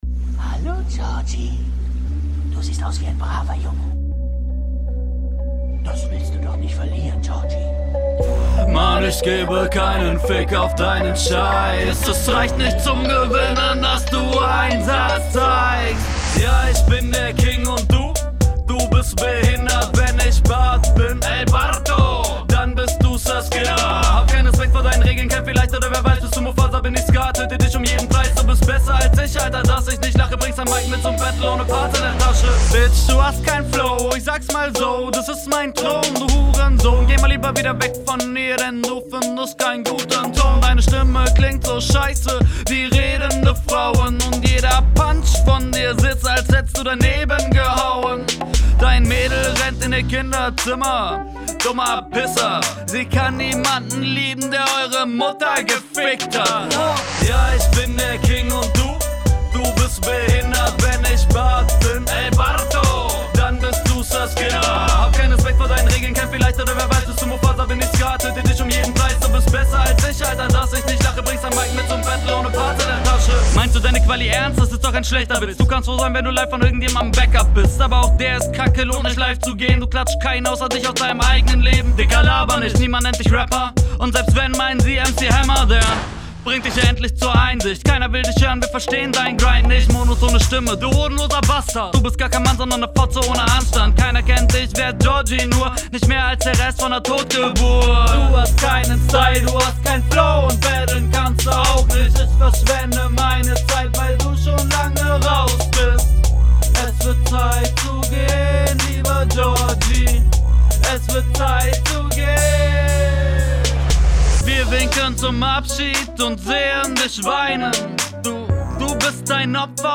Dieses Psyschomäßige feier ich.